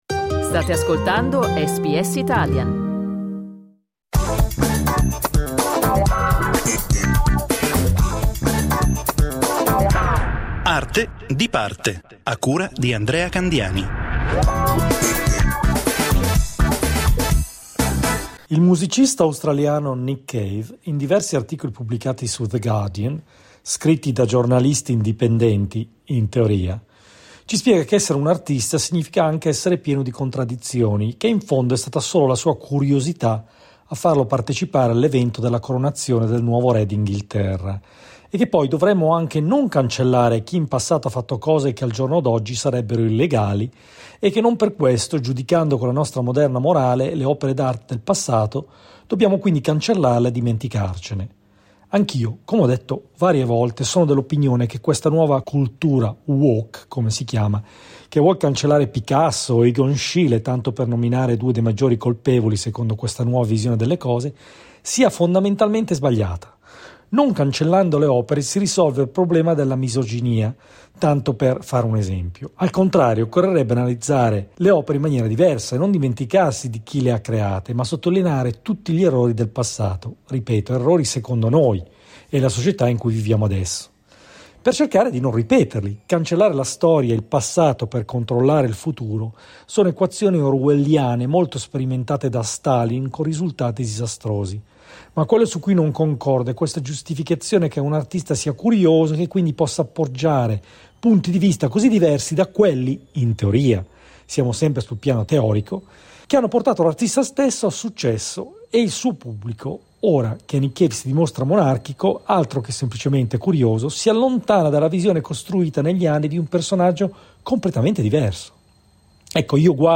Clicca sul tasto 'play' in alto per ascoltare il commento del critico